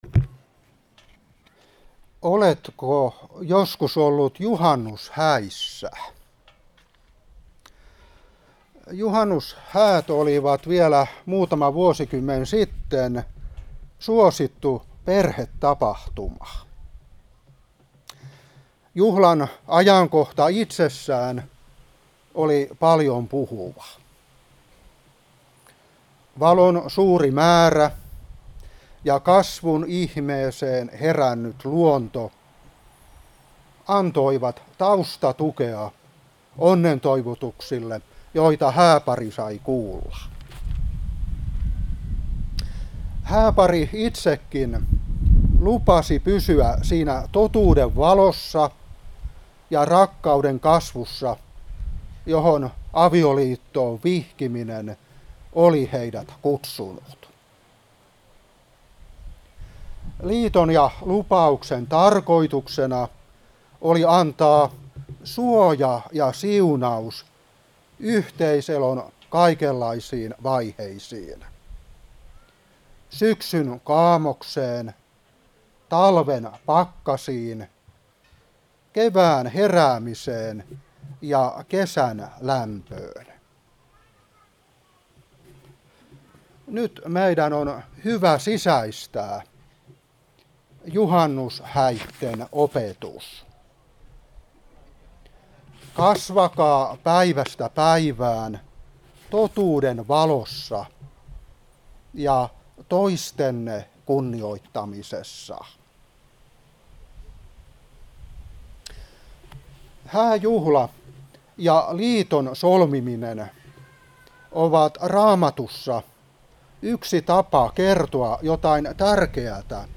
Seurapuhe 2023-6.